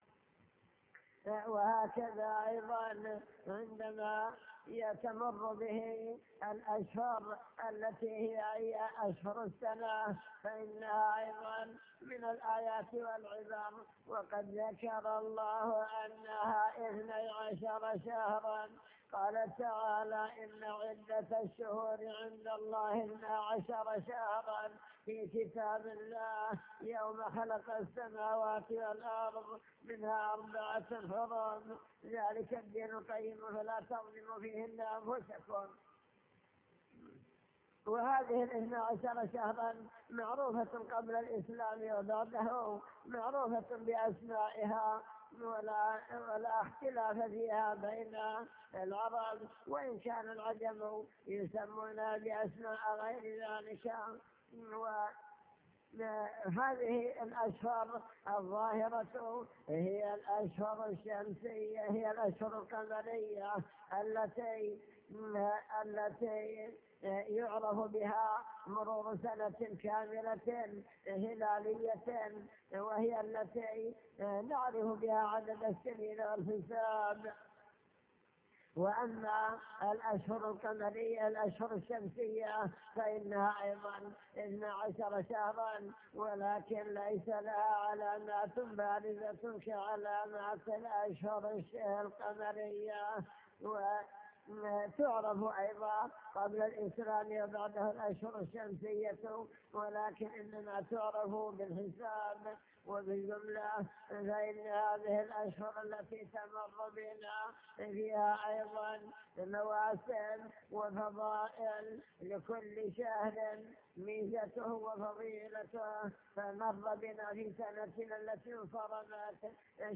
المكتبة الصوتية  تسجيلات - محاضرات ودروس  محاضرة بعنوان المسلم بين عام مضى وعام حل